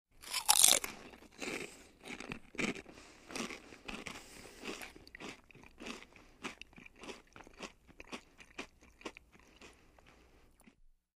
Звуки еды
Хруст и аппетитное жевание картофельных чипсов